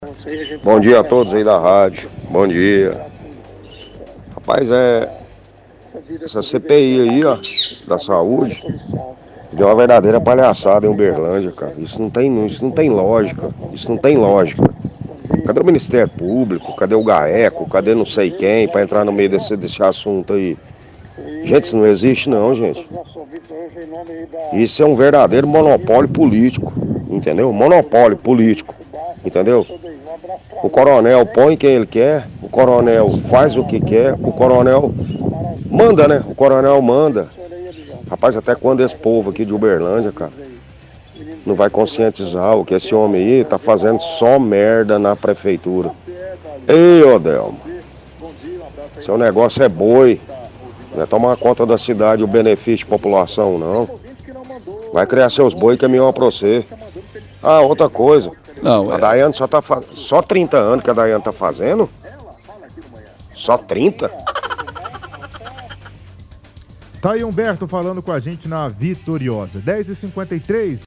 – Ouvinte critica CPI da saúde, dizendo que o prefeito coloca quem quer e “só faz merda na cidade”. Questiona onde está o MP ou o Gaeco para fazer alguma coisa.